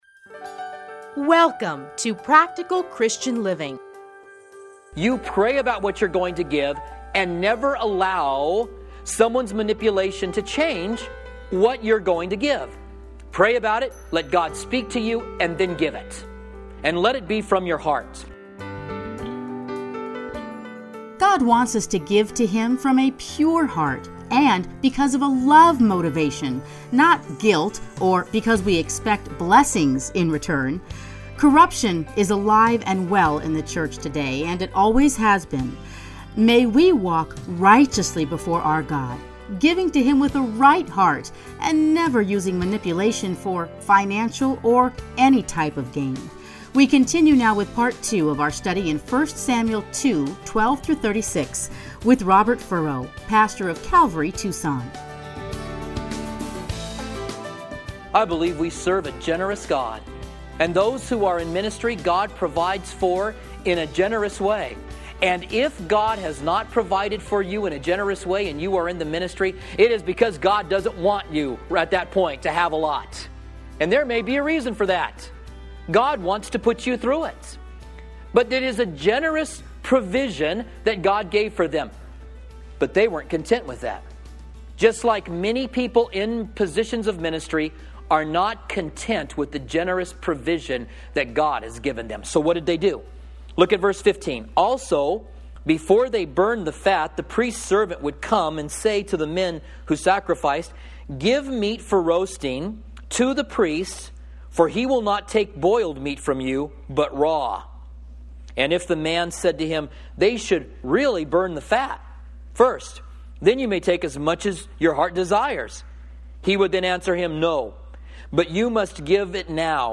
teachings are edited into 30-minute radio programs titled Practical Christian Living.